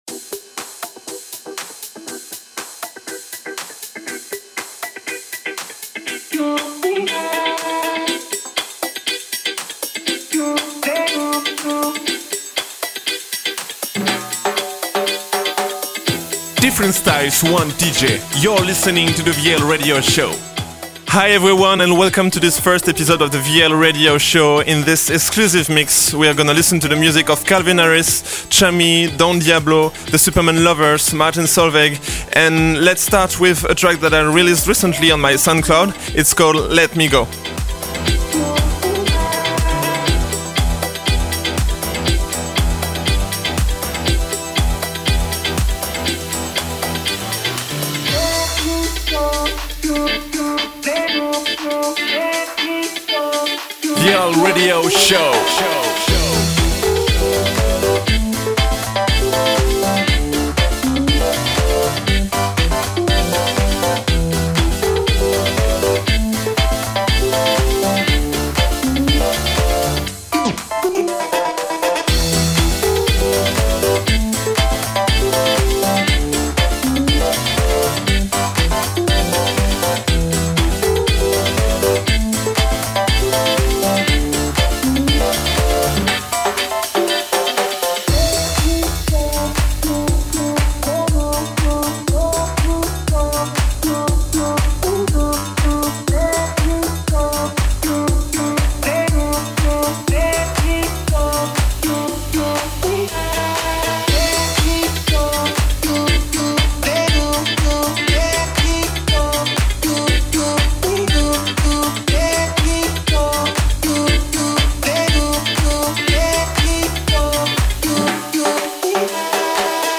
Dance, house & future house DJ mix.